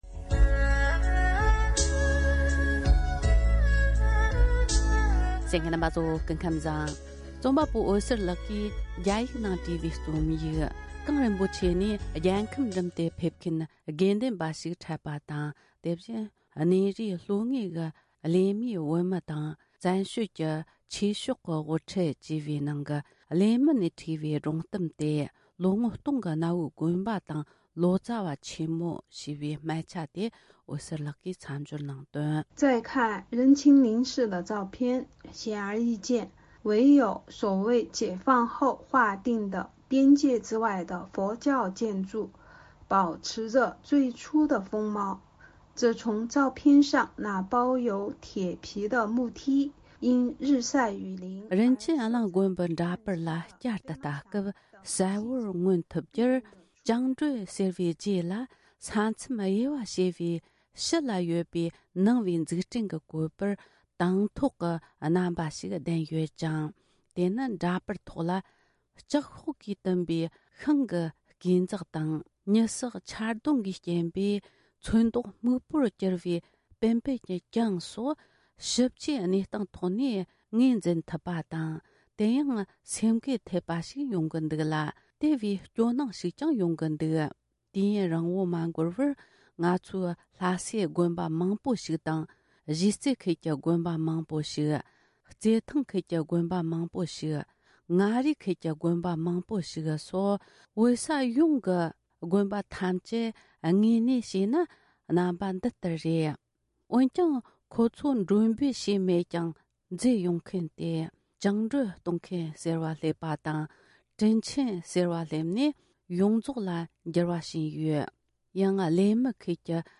གངས་རིན་པོ་ཆེ་ནས་མཇལ་པའི་དགེ་འདུན་པ་ཞིག་དང་། དེ་བཞིན་གནས་རིའི་ལྷོ་ངོས་ཀྱི་གླེ་མིའི་བོད་མི་དང་བཙན་བྱོལ་གྱི་ཆོས་ཕྱོགས་དབུ་ཁྲིད། ཨམ་སྐད།